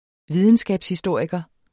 Udtale [ ˈviðənsgabs- ]